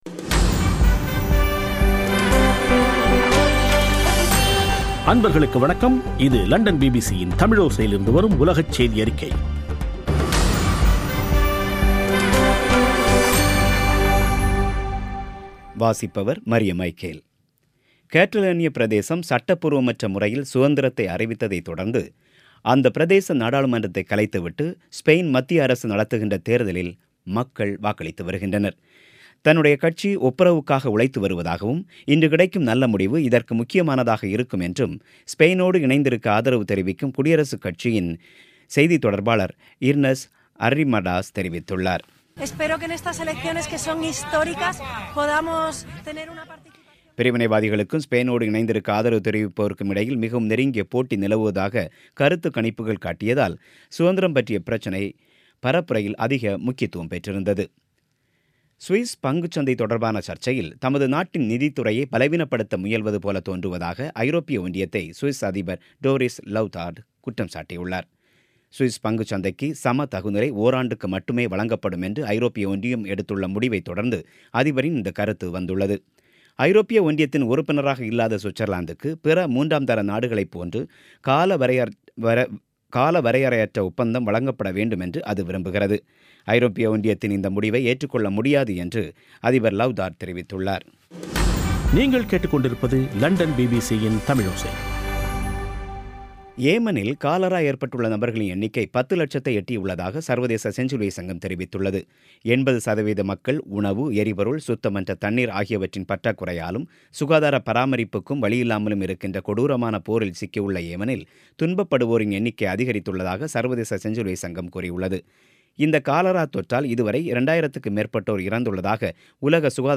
பிபிசி தமிழோசை செய்தியறிக்கை (21/12/2017)